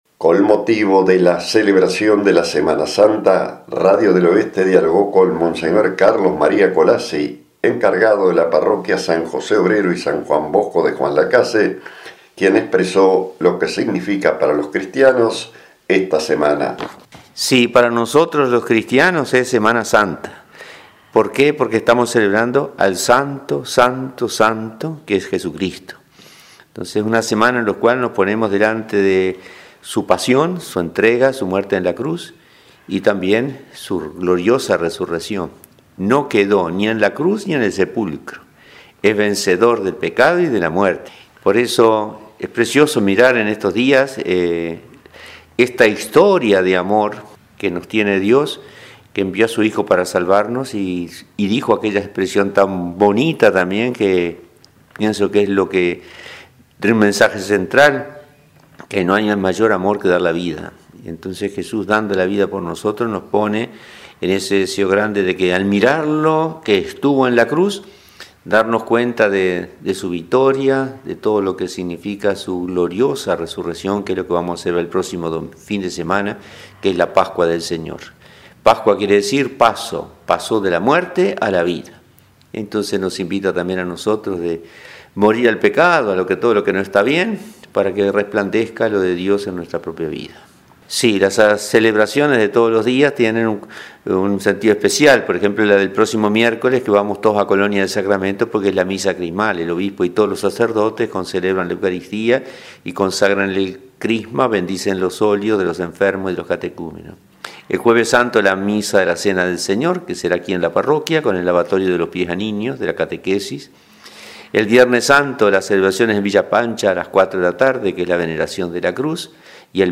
Con motivo de la celebración de la semana santa, Radio del Oeste dialogó con el monseñor Carlos María Collazzi, encargado de la Parroquia San José Obrero y San Juan Bosco, quien expresó lo que significa para los cristianos esta semana.